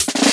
014_XOQ_Snare_Fill.wav